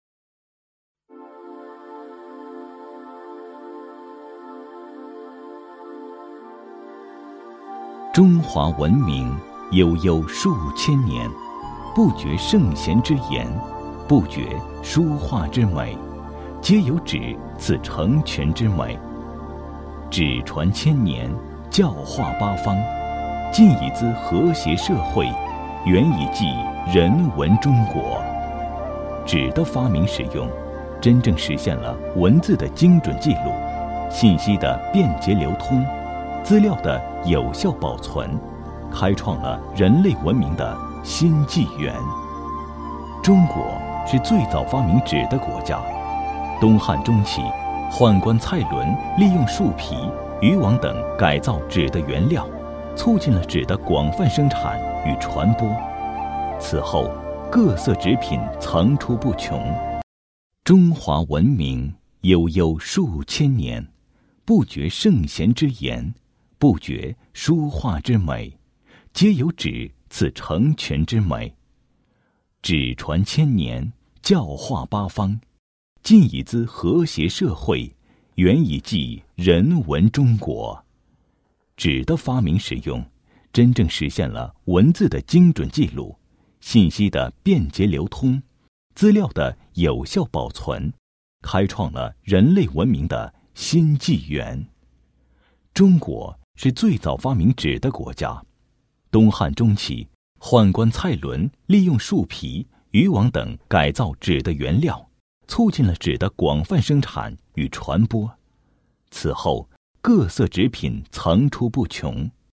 人文历史纪录片配音